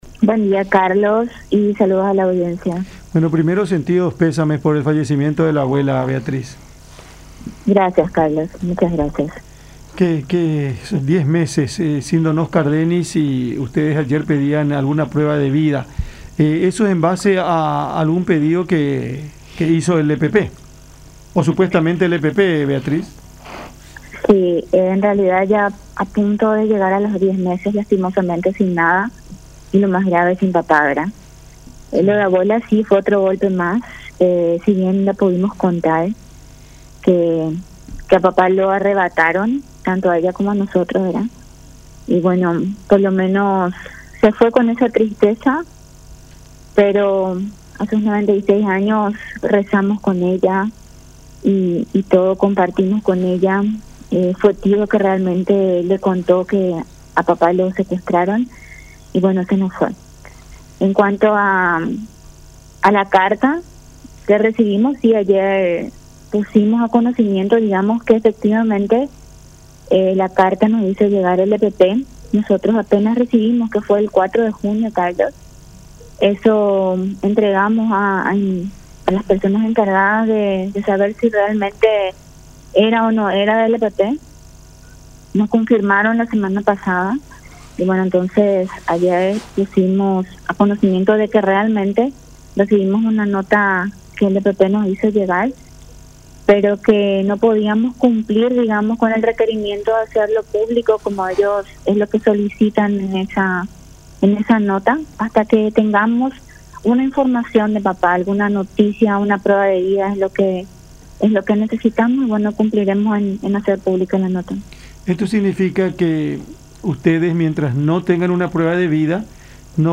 Estamos a punto de llegar a los 10 meses y sin ninguna novedad, así que pedimos primero una prueba de vida y después vamos a publicar el contenido”, dijo en diálogo con Cada Mañana por La Unión.